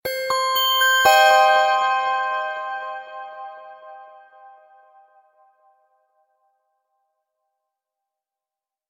Затухающий ЗВУК для SMS